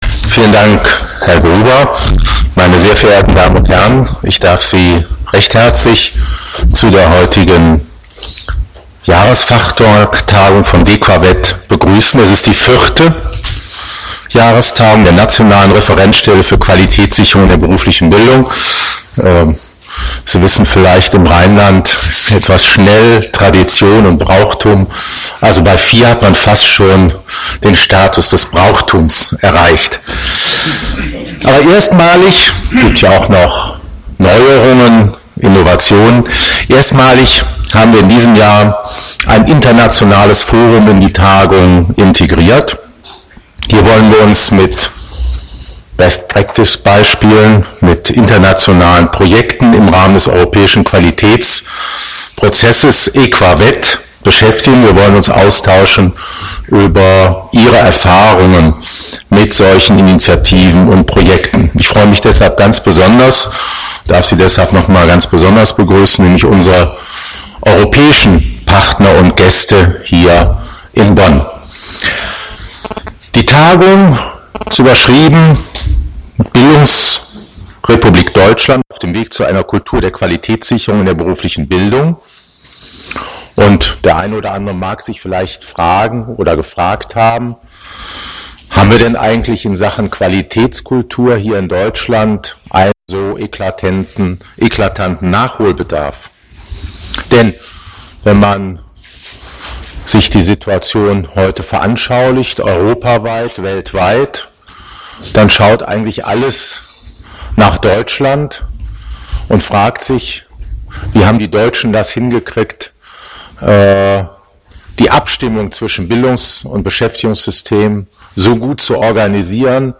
Begrüßung